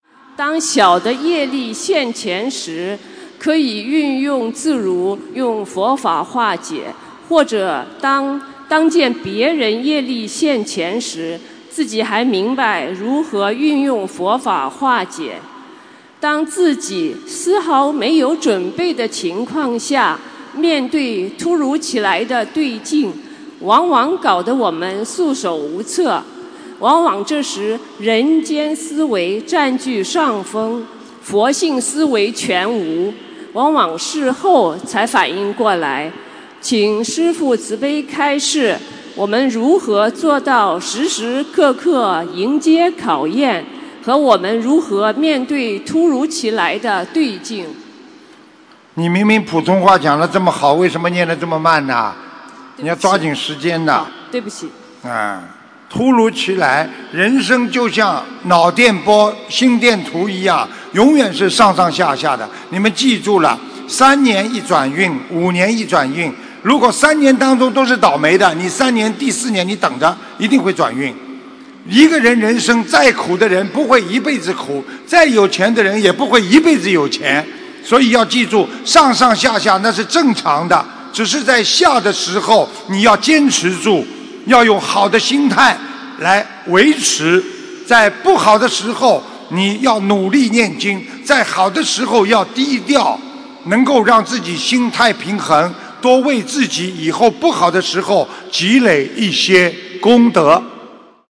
运程每隔三五年会转变，如何面对顺境和逆境┃弟子提问 师父回答 - 2017 - 心如菩提 - Powered by Discuz!